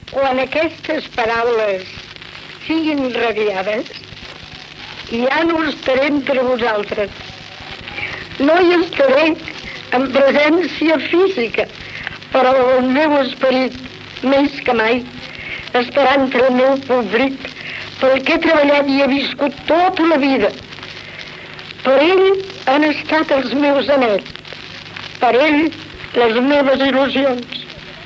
Testament radiofònic de l'actriu Maria Morera emès després de la seva mort.